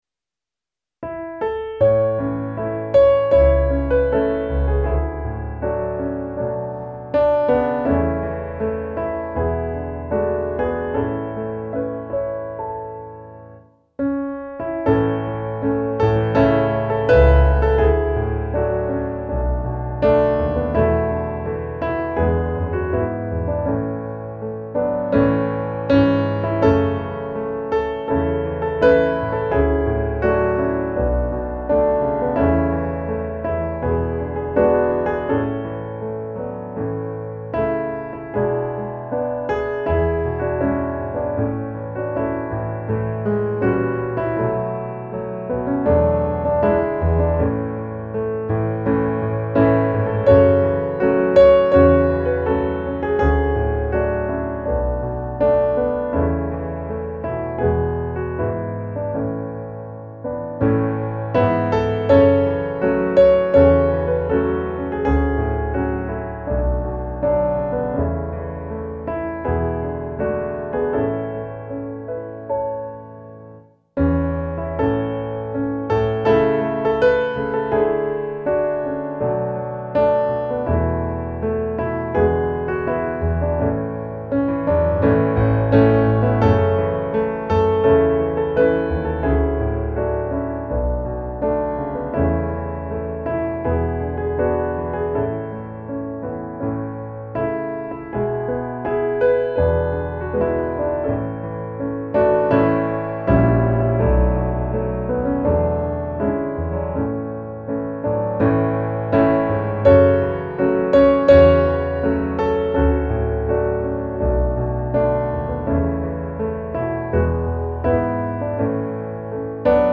Var jag går i skogar, berg och dalar - musikbakgrund
Musikbakgrund Psalm